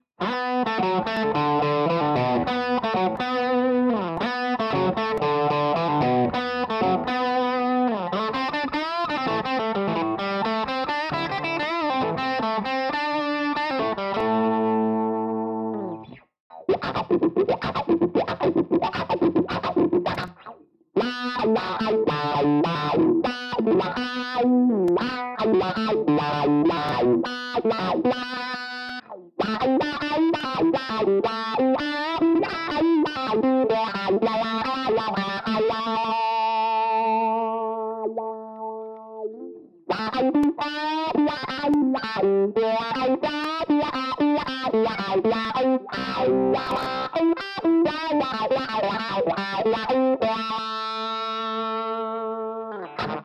Setup was Burny Les Paul copy (Bridge pickup) -> Wah -> Toneport UX-2 (1968 Plexi Lead 100 Amp, Noise gate, no effects) -> Reaper. First 10 seconds or so are with the wah off. Just some random pentatonic wanking and a well know wah snippet. Apologies for the little blasts of noise, not sure where it came from, levels are well below clipping. http
wah demo.mp3